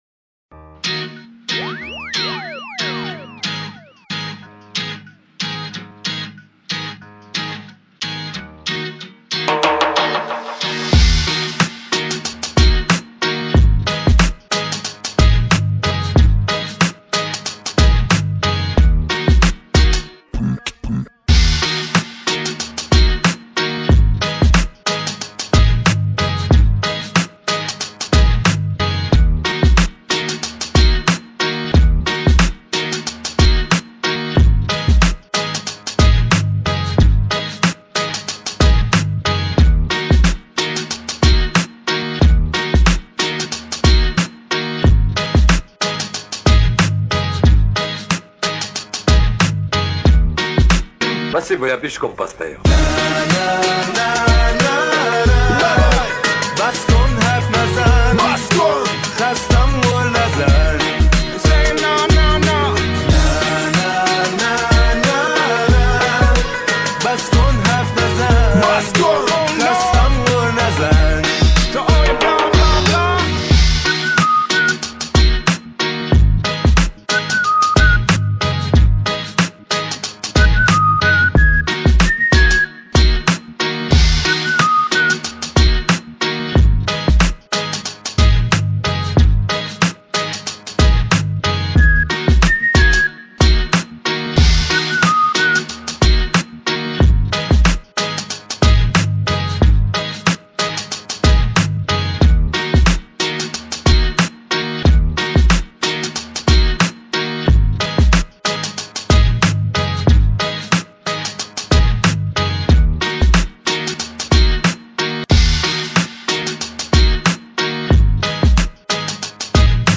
Профессионально написанный минус